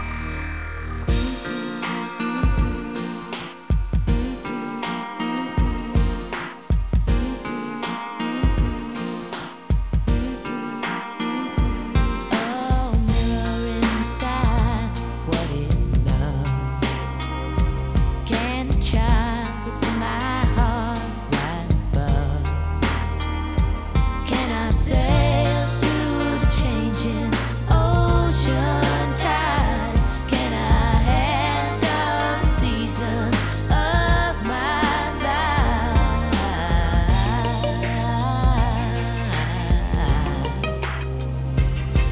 • String Remix
string remix (Wav, 900K)